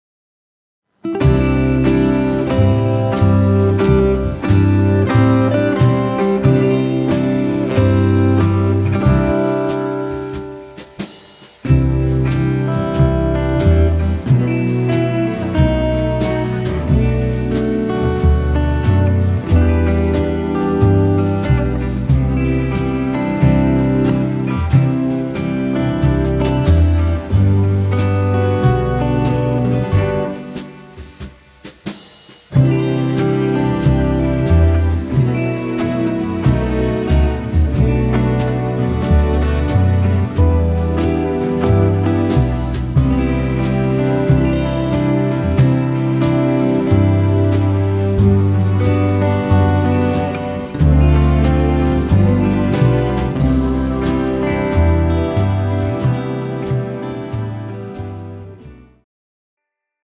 slow country swing style
tempo 110 bpm
female backing track